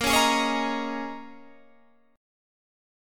Bbm11 chord